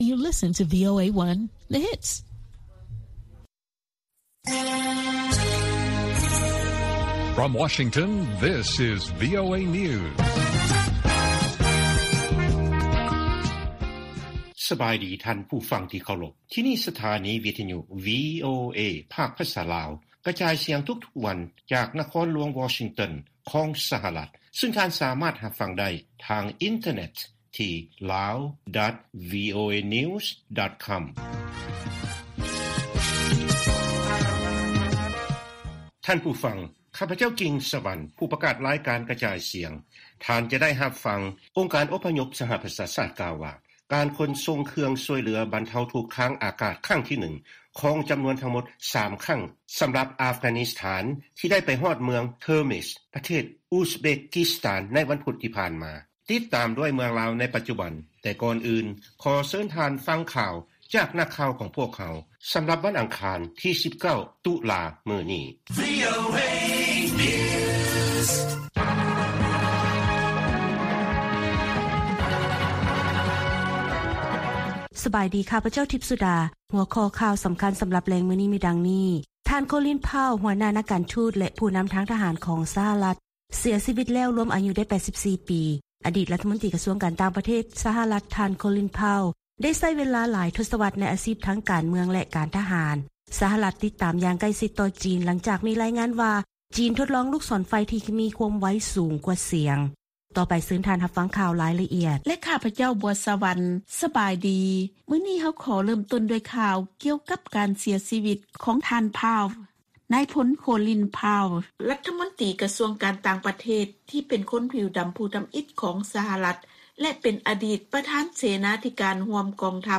ລາຍການກະຈາຍສຽງຂອງວີໂອເອລາວ: ຄະນະປະຈໍາສະພາແຫ່ງຊາດລາວ ຢືນຢັນວ່າ ການບໍລິຫານຂອງລັດຖະບານລາວ ຈະຕ້ອງປະຕິບັດຕາມກົດໝາຍ.